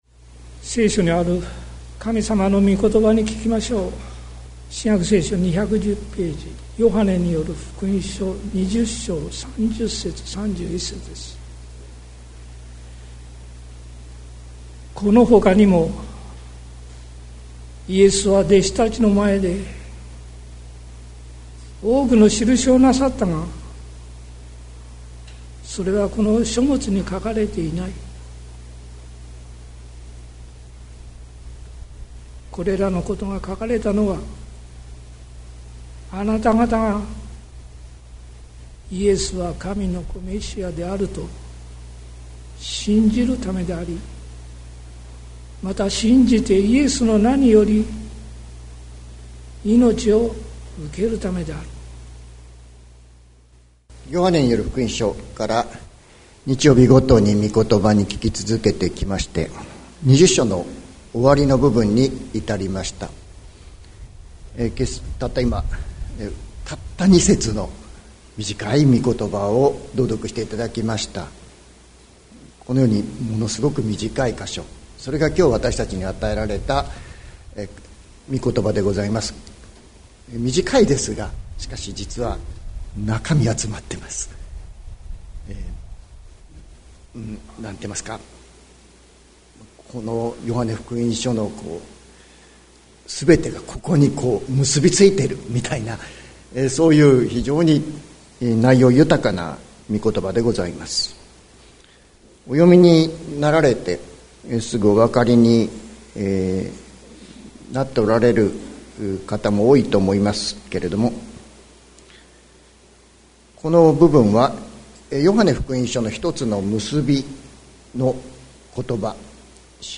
2024年03月03日朝の礼拝「ここに命の道がある」関キリスト教会
説教アーカイブ。